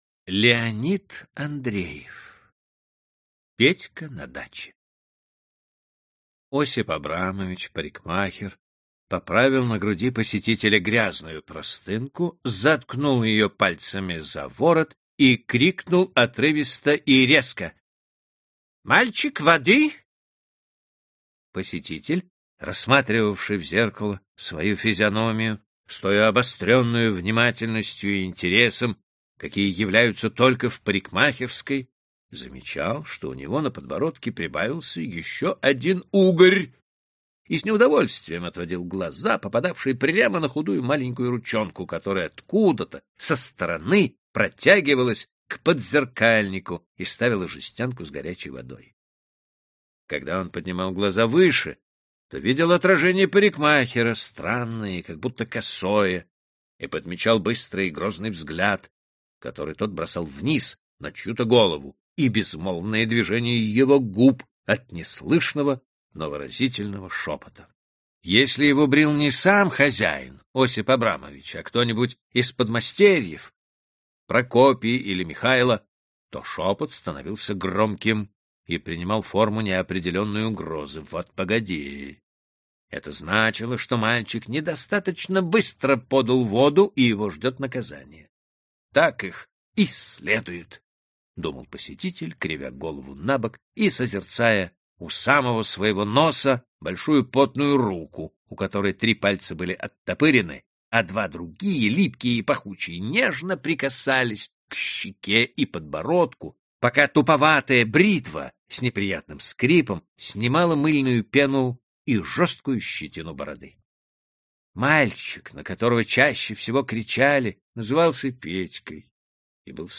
Аудиокнига Классика русского рассказа № 10 | Библиотека аудиокниг